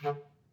operation-theatre / Clarinet / stac / DCClar_stac_D2_v1_rr2_sum.wav
DCClar_stac_D2_v1_rr2_sum.wav